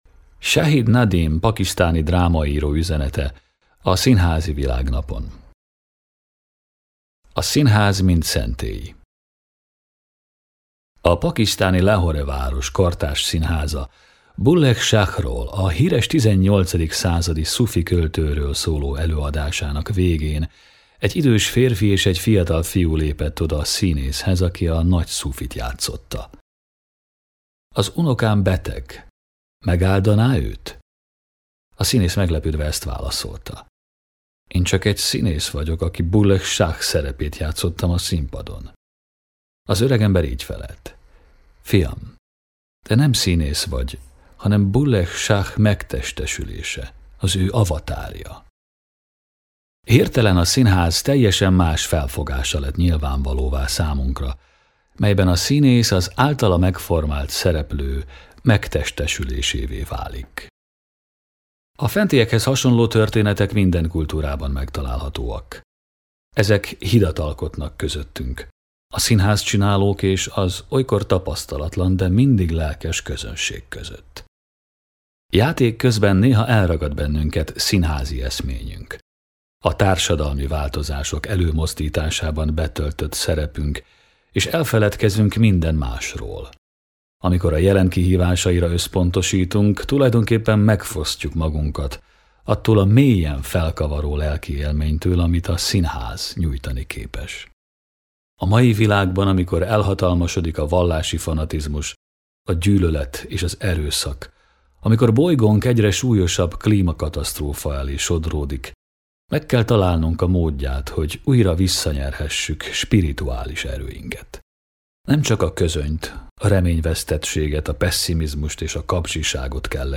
Színházi Világnapi üzenet